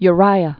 (y-rīə)